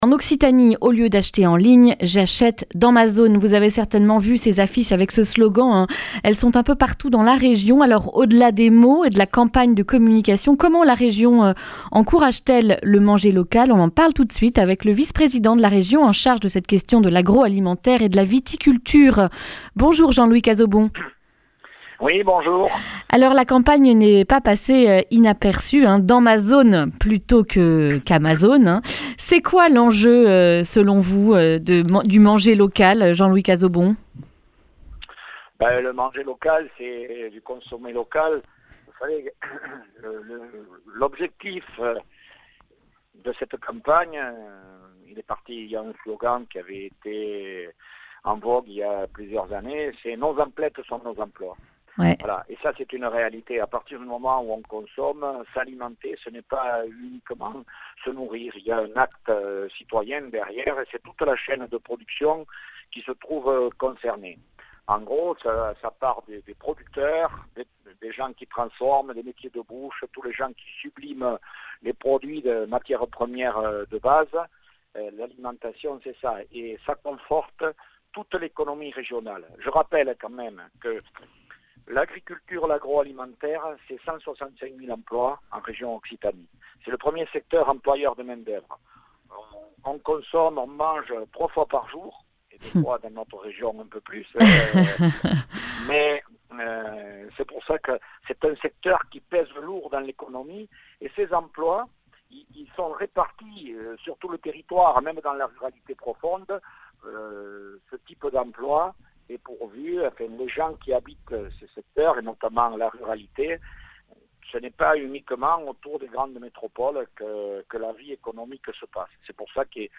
mercredi 4 mars 2020 Le grand entretien Durée 11 min